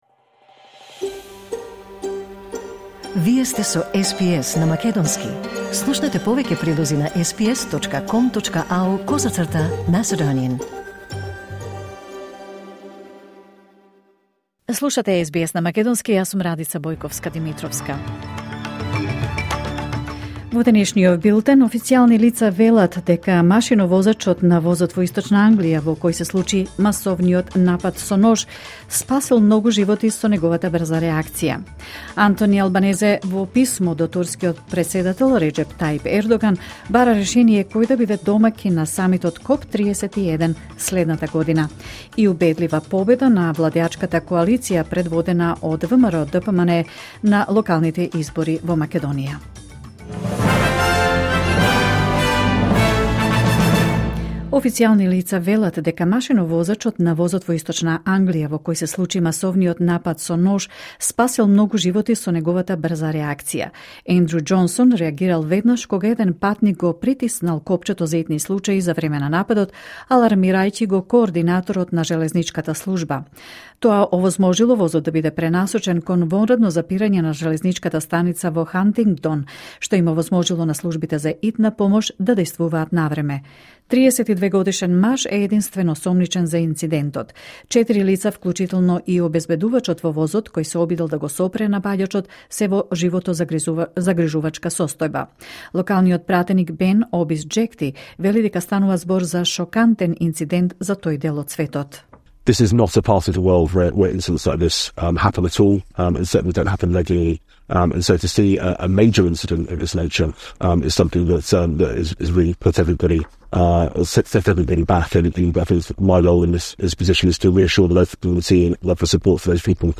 Вести на СБС на македонски 3 ноември 2025